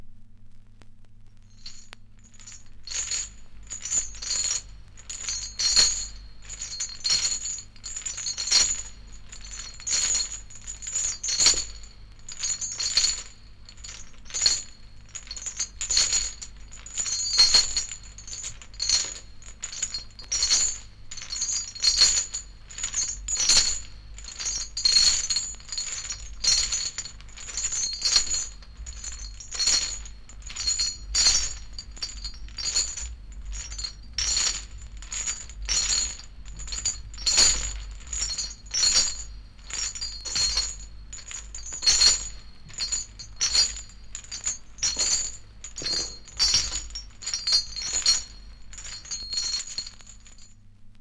• scary footsteps with chains - prison.wav
scary_footsteps_with_chains_-_prison_DUc.wav